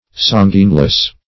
Sanguineless \San"guine*less\, a.
sanguineless.mp3